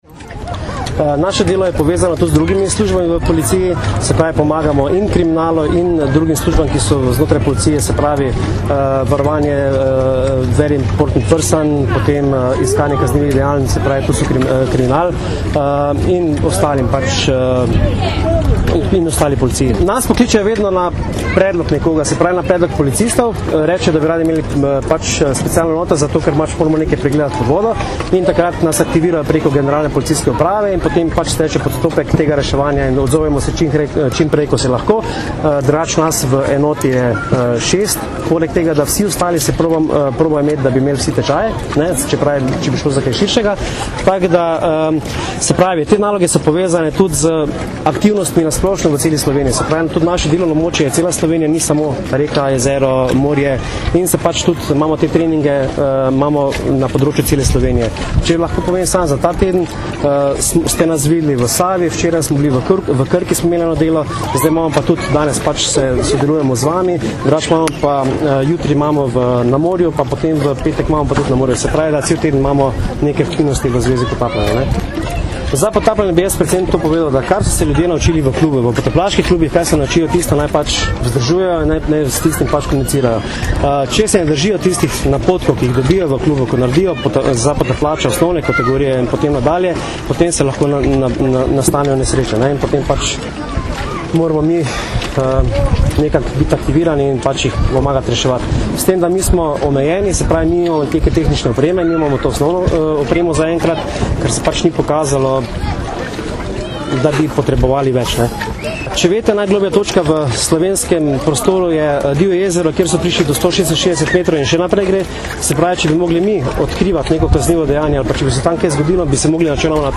Ob tej priložnosti smo danes, 22. julija 2008, v regatnem centru Zaka na Bledu pokazali tudi delopolicistov potapljačeviz Specialne enote.